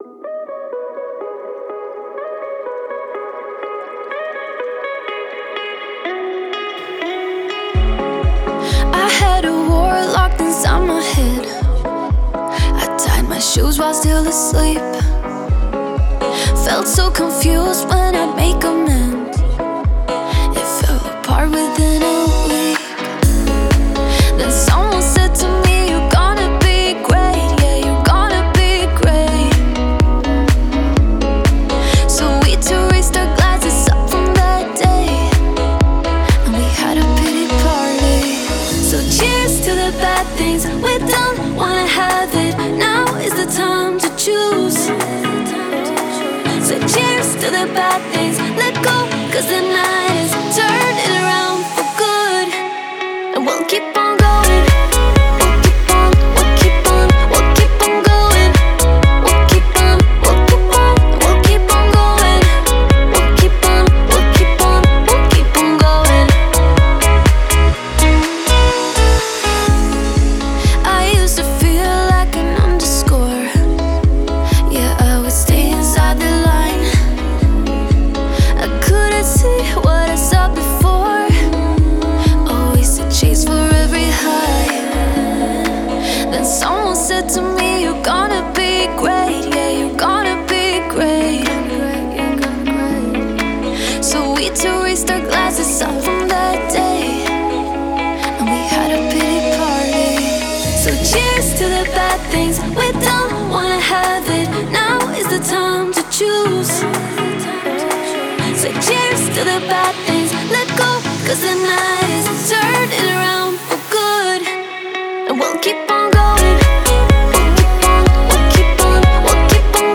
это зажигательная композиция в жанре EDM